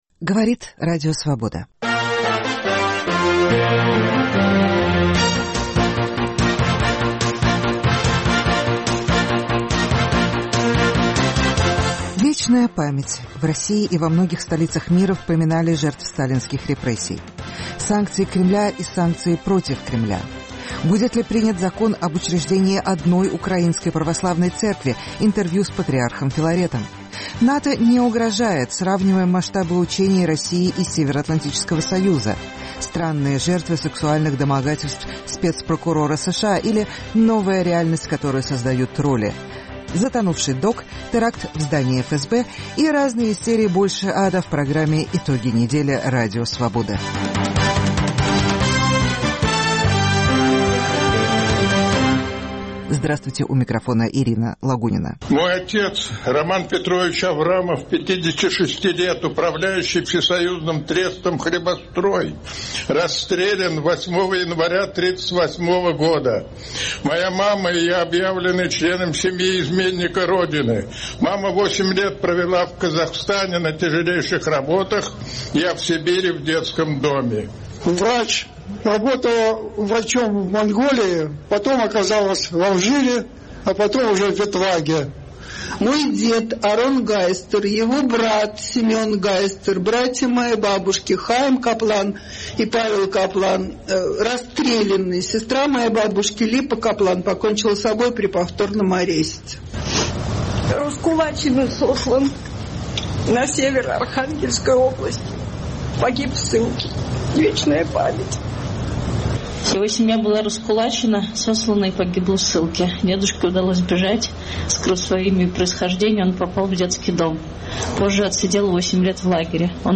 Санкции Кремля и санкции против Кремля.*** Будет ли принят закон об учреждении одной Украинской православной церкви. Интервью с патриархом Филаретом.